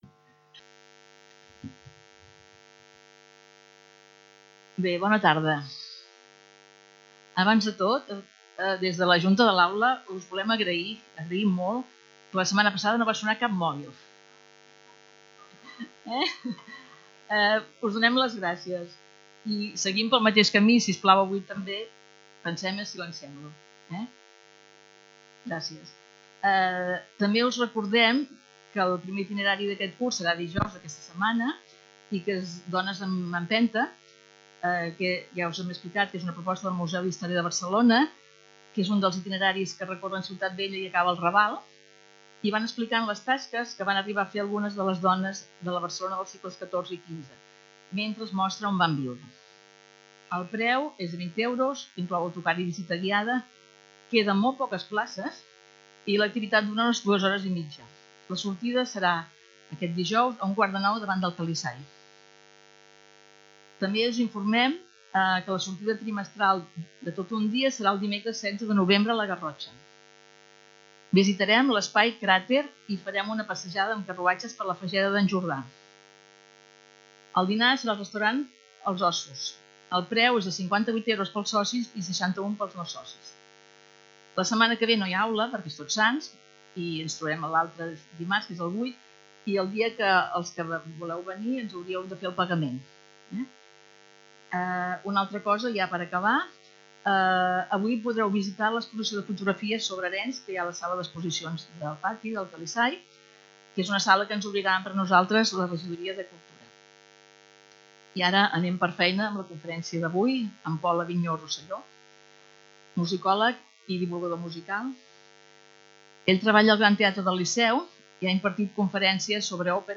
Lloc: Centre Cultural Calisay